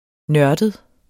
Udtale [ ˈnɶɐ̯dəð ]